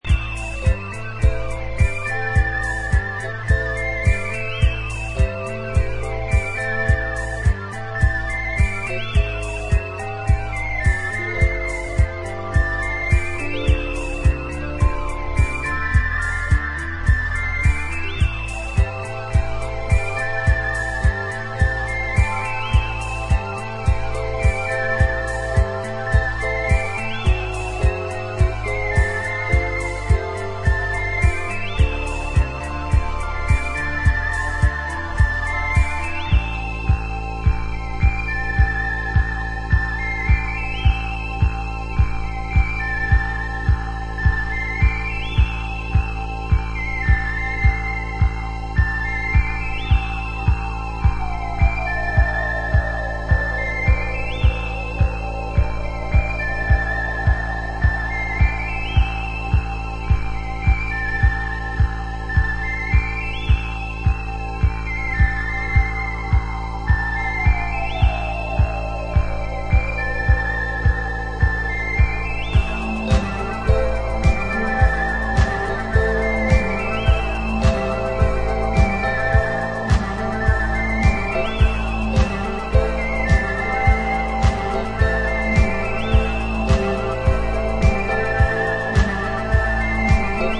new age disco
with sci-fi synth leads